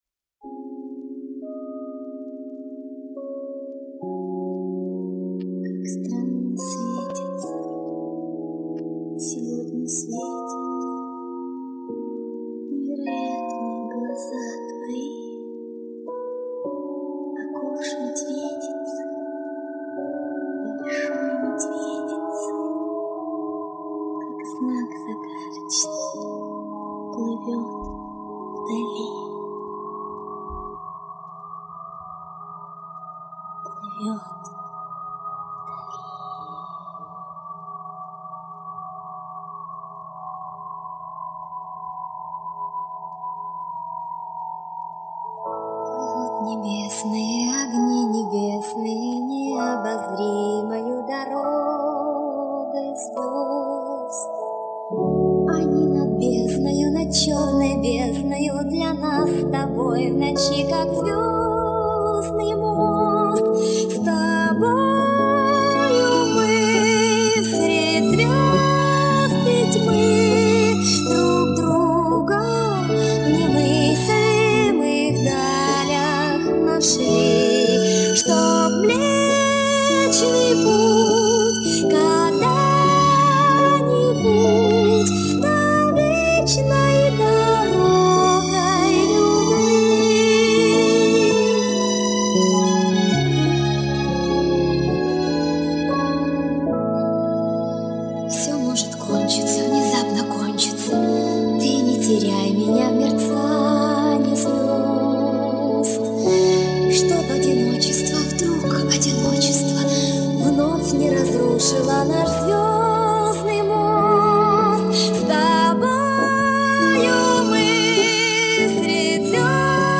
И я считаю, что эти песни записаны не самым лучшим образом.
Мой голос звучал чуть выше.